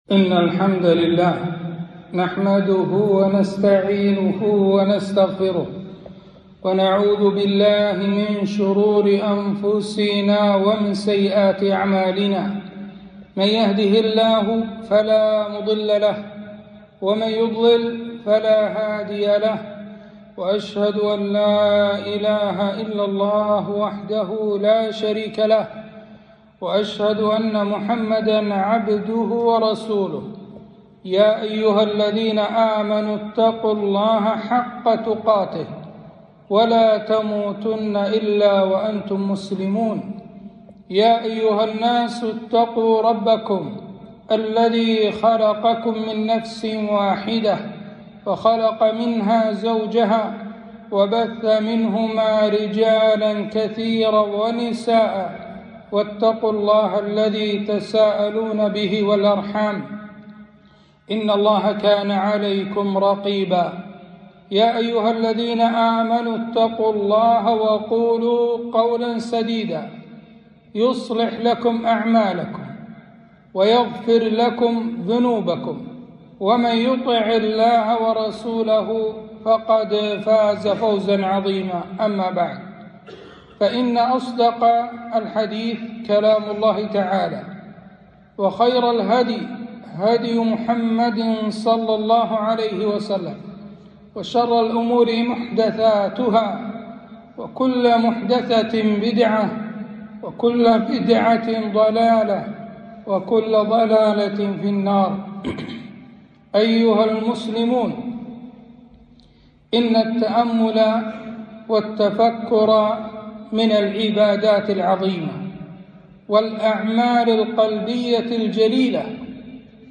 خطبة - التفكر في خلق الله